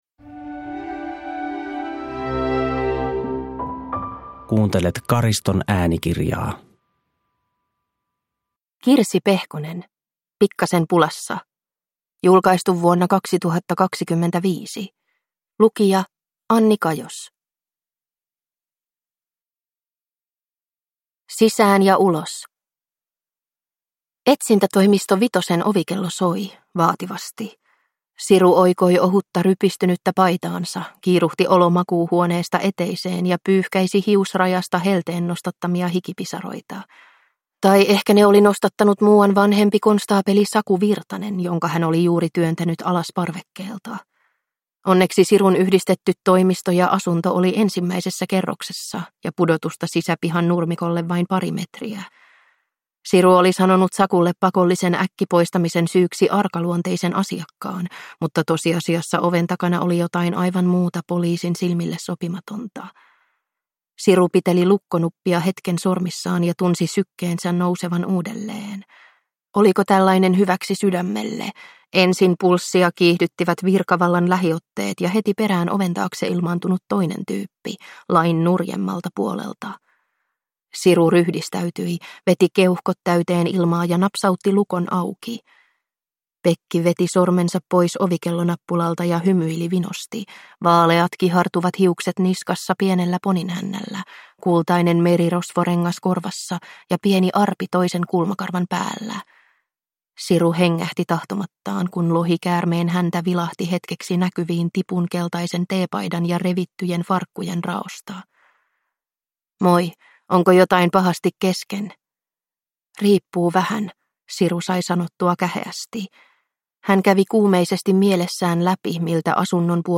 Pikkasen pulassa (ljudbok) av Kirsi Pehkonen